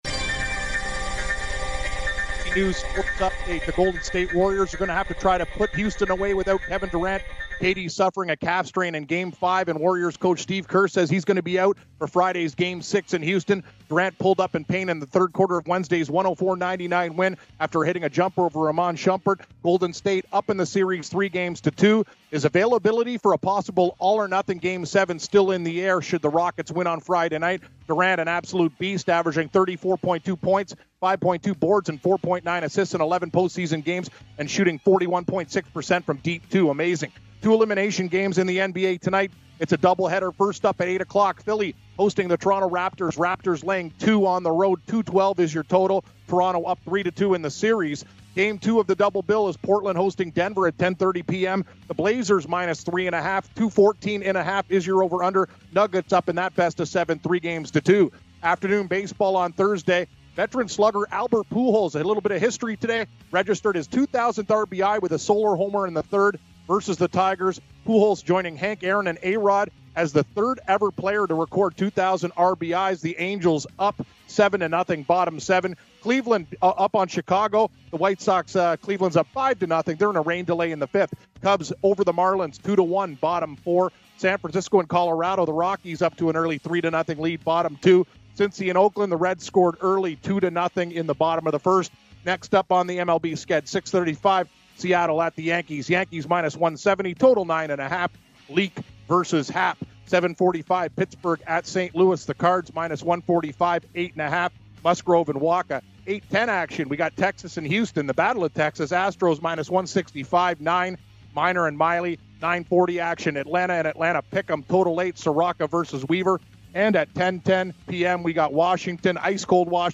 Re-air of Rick Barry Interview